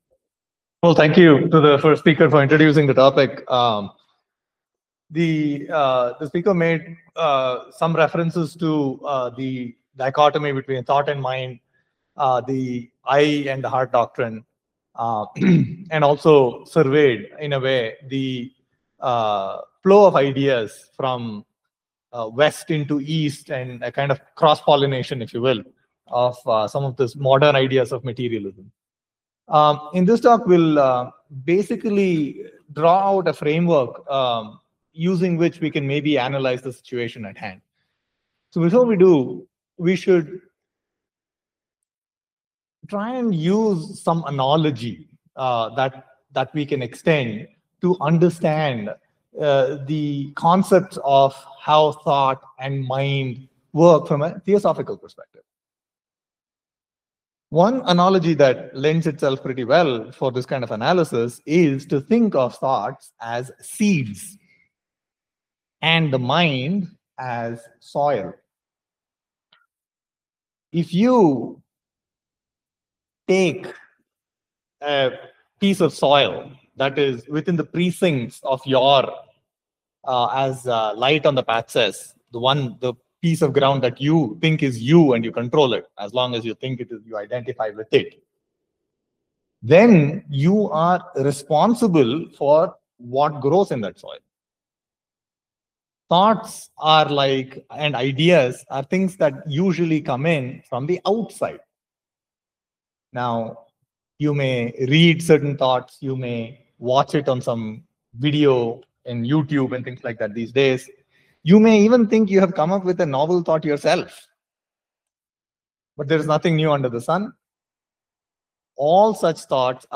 Uploaded Audio Lecture: Eastern Thought and the Western Mind
Dear Subscriber, An Audio Lecture entitled Eastern Thought and the Western Mind has been recorded and uploaded to our web site.